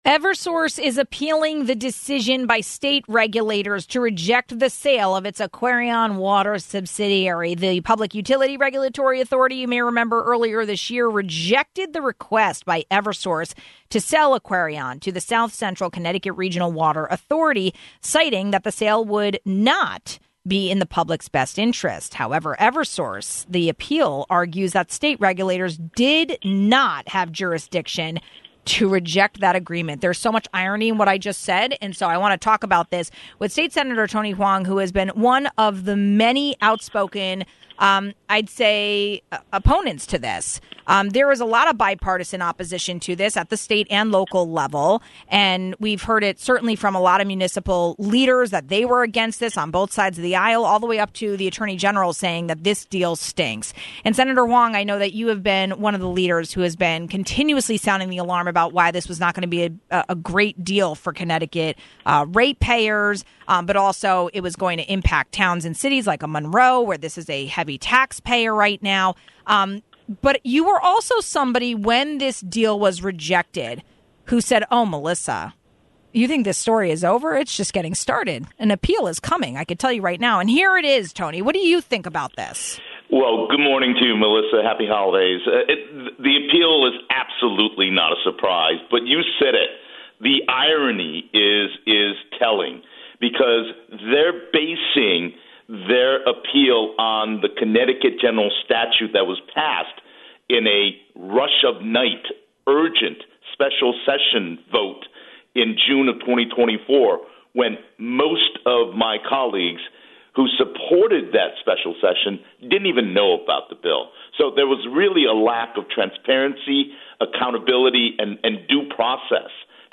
Eversource's appeal argues that state regulators did not have jurisdiction to reject the agreement. We talk about the irony of that claim with Senator Tony Hwang.